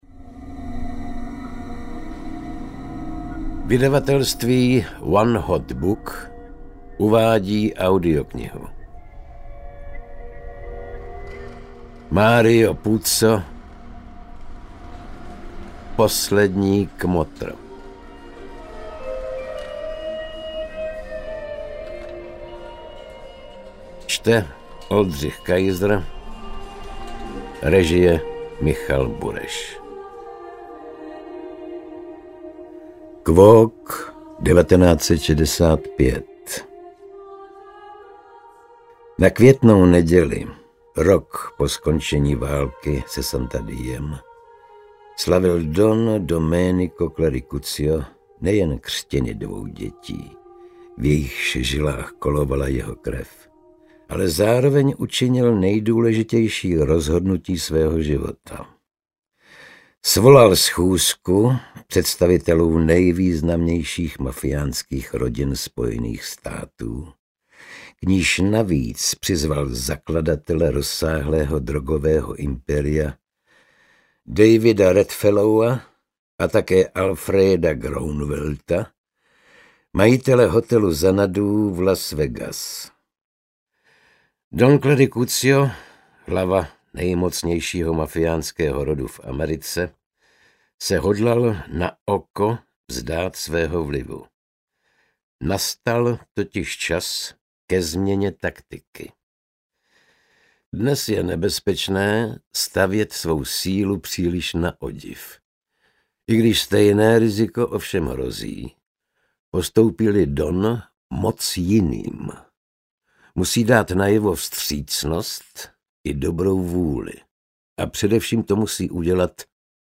Poslední kmotr audiokniha
Ukázka z knihy
• InterpretOldřich Kaiser